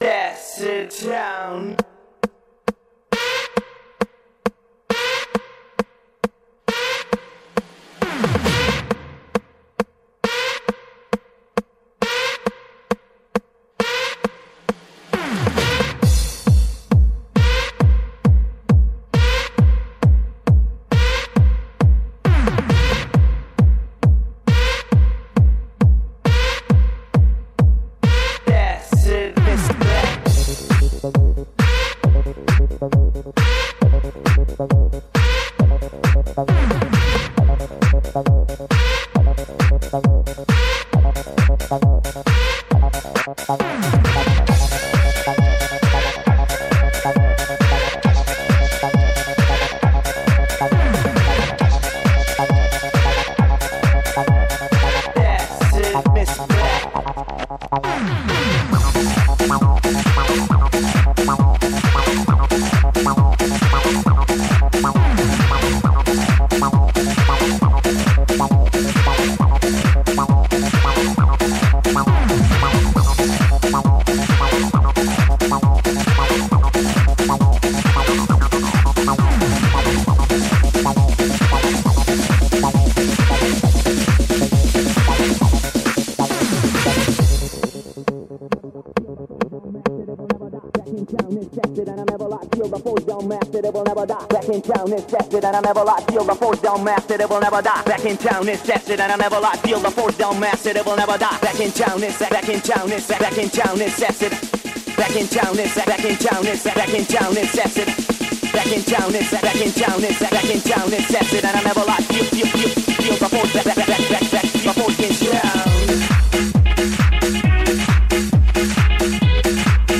Жанр: Techno